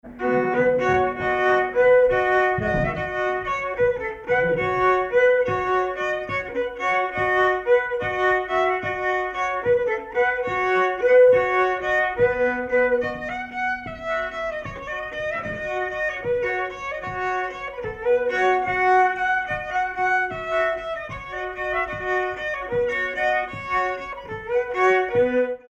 Danse
Villard-sur-Doron
circonstance : bal, dancerie
Pièce musicale inédite